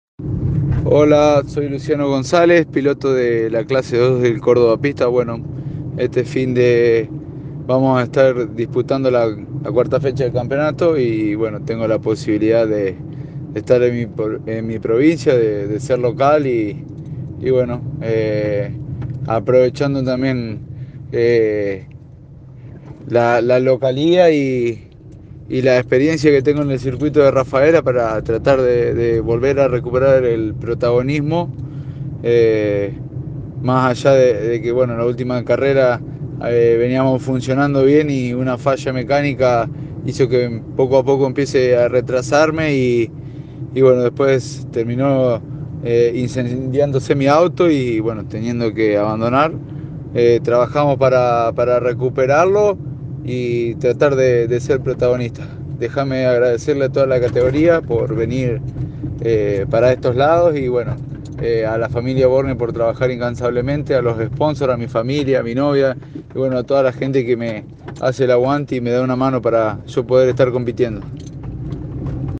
Los pilotos santafesinos realizaron declaraciones antes de la quinta fecha.